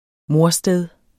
Udtale [ ˈmoɐ̯- ]